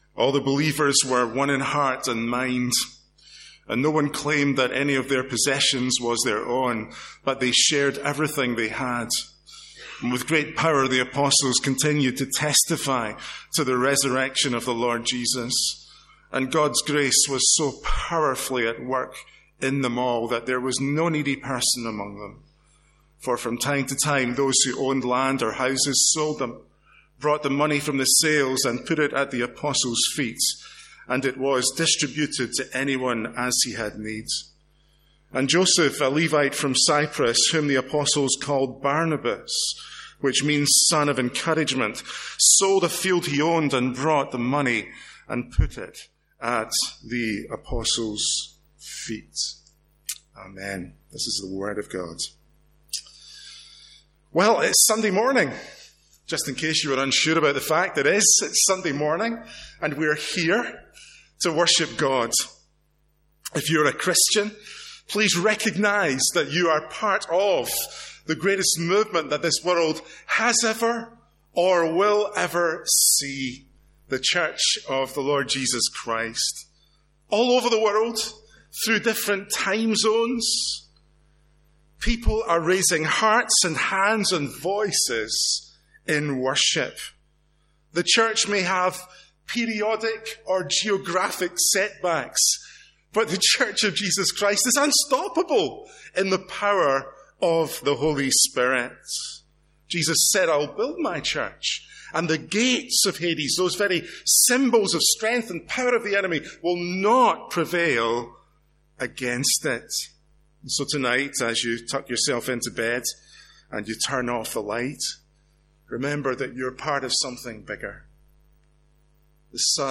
A one-off sermon from our morning service.